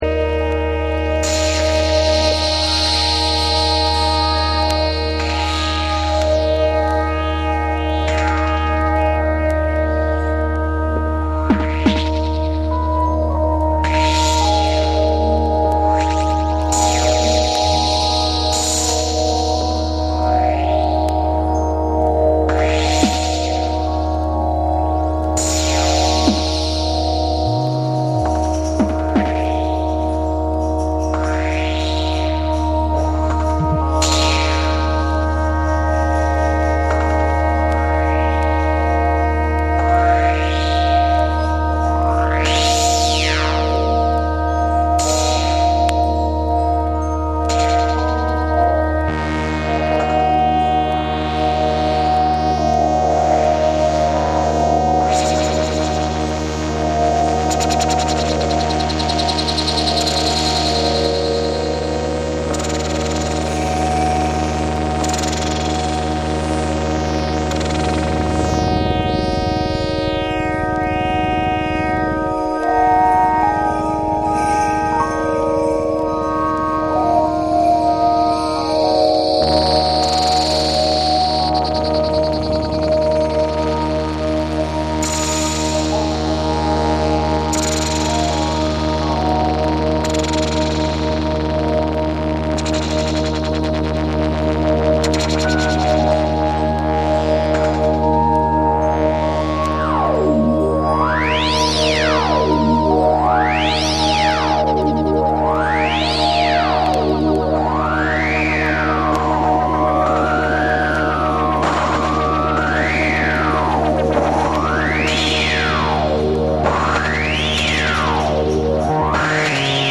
混沌とした空気感とエレクトロニクスが融合した実験性の高いナンバーを収録。
BREAKBEATS